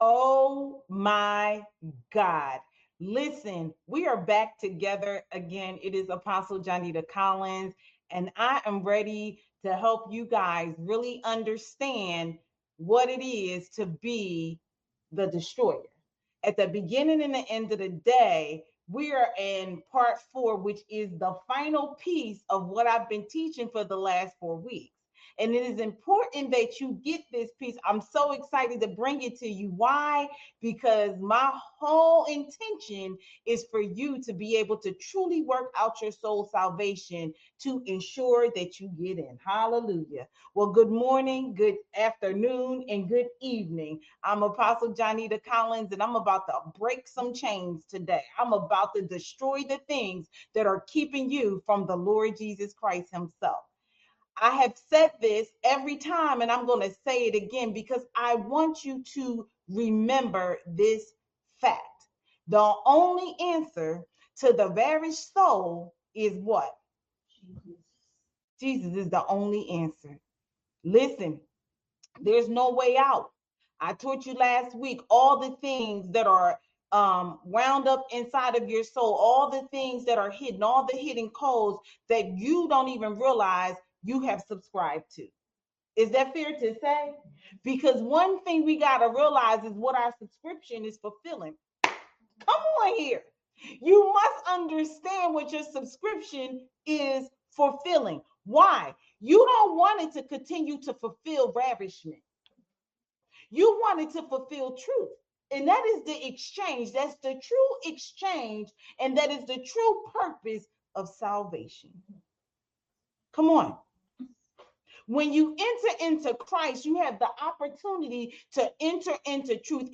I AM THE DESTROYER! PART IV Reign Embassy Sunday Morning Services podcast